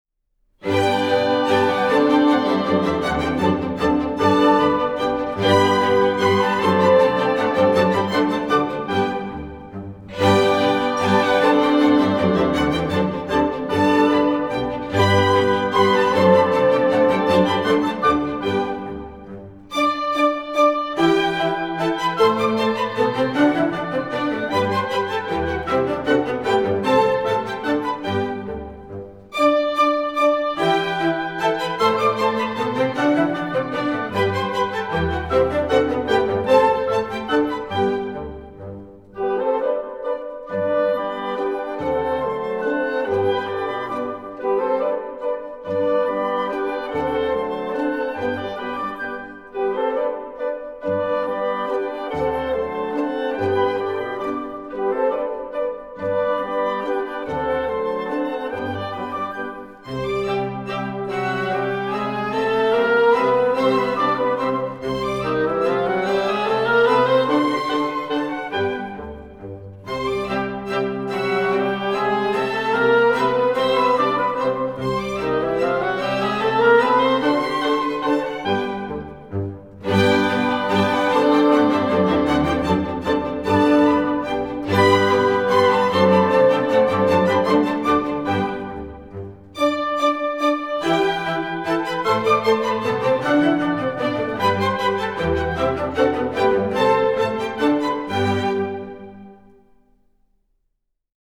Orquesta
Música clásica